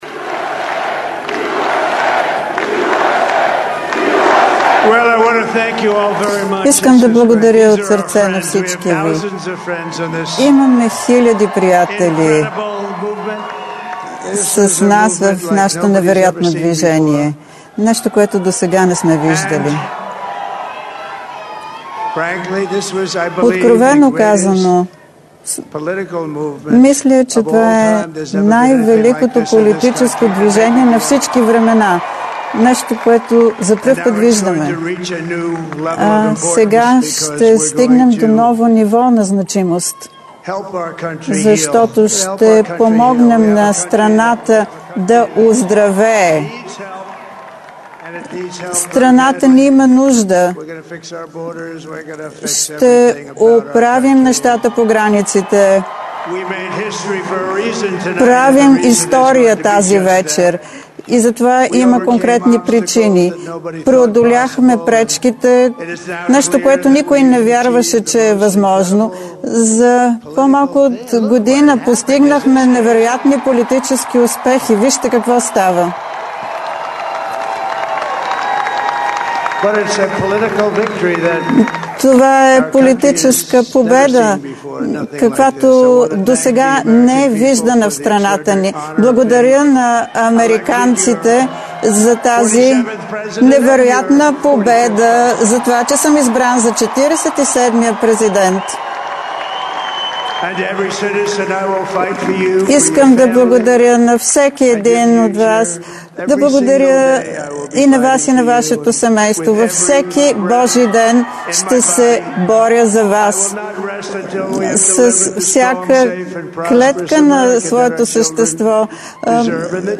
10.10 - Пресконференция на „Съюз Такси", Национален таксиметров синдикат и партньори на тема: „Национален таксиметров протест на 20.11.2024 г. срещу вдигането на застрахователната премия „Гражданска отговорност" за таксиметровите автомобили от 50% до 1 100%"
Директно от мястото на събитието